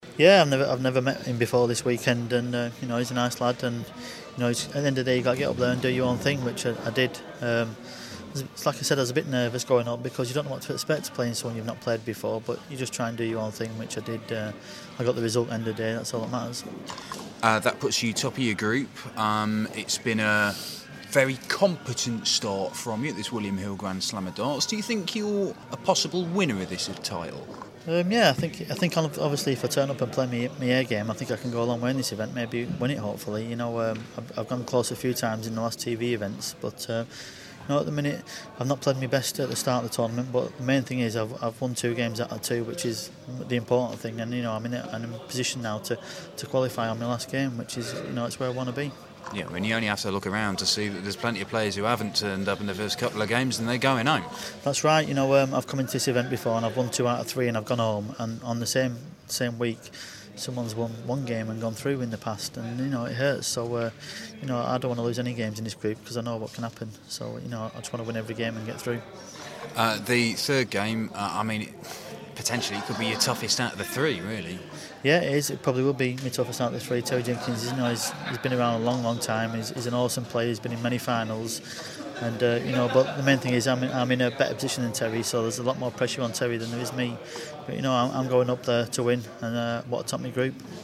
William Hill GSOD - Newton Interview (2nd game)